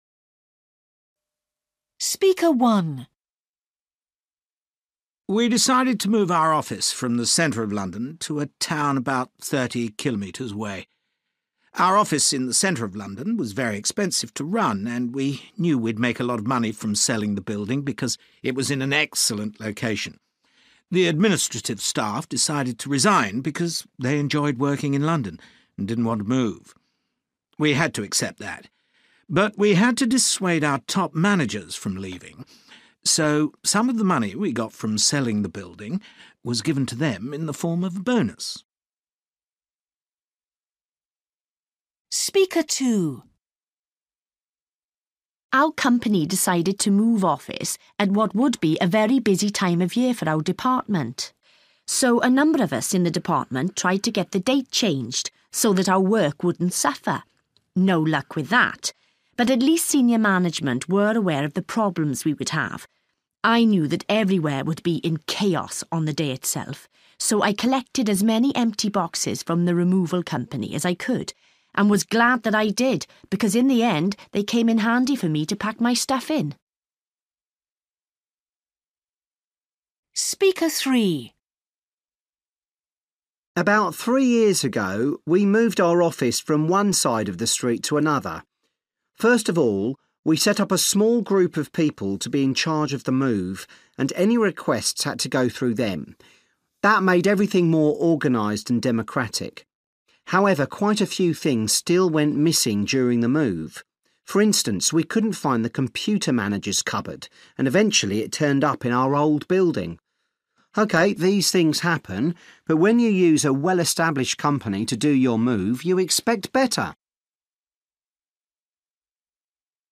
You will hear five short extracts in in which people are talking about when they moved their office from one building to another.